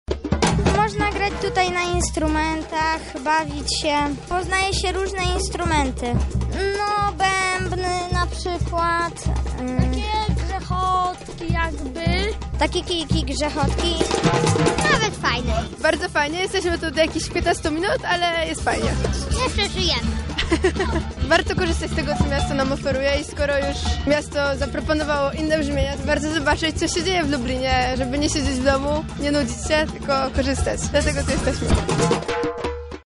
Na Błoniach pod Zamkiem odbyły się zajęcia z gry na największym bębnie świata.
Była tam nasza reporterka.